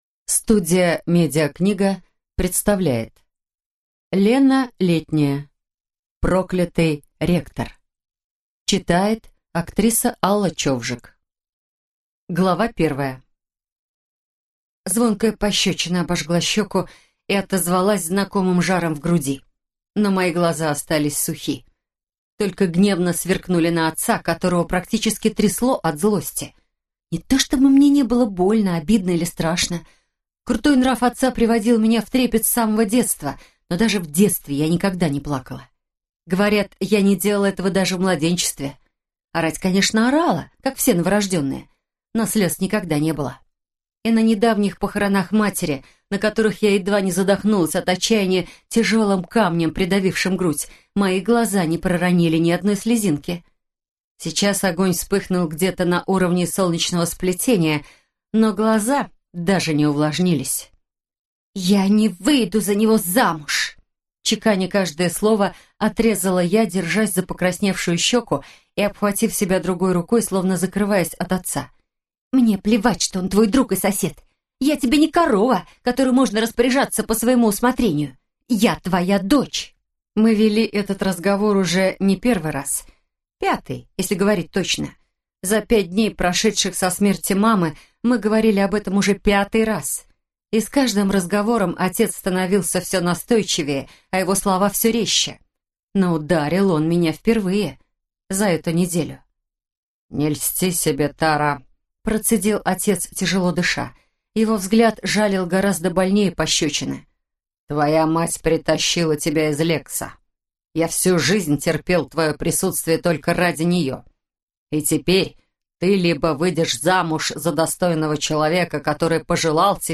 Аудиокнига Проклятый ректор - купить, скачать и слушать онлайн | КнигоПоиск